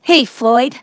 ovos-tts-plugin-deepponies_Spike_en.wav